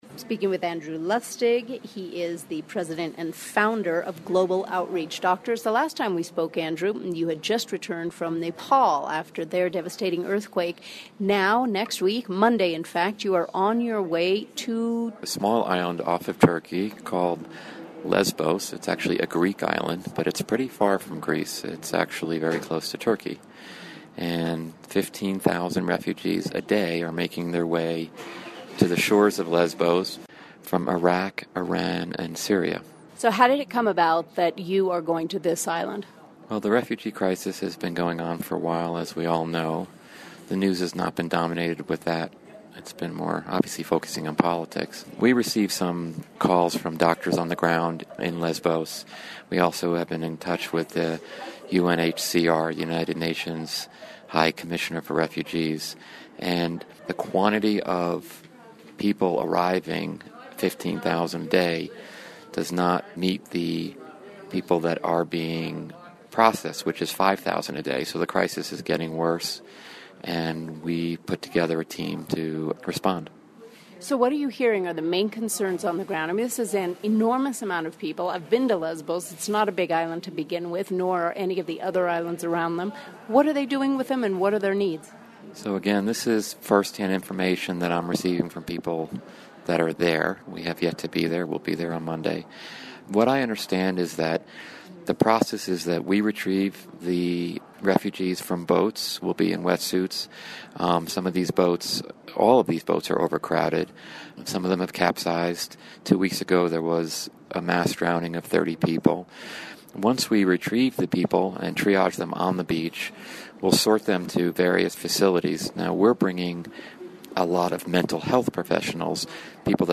Interview: Syrian refugee crisis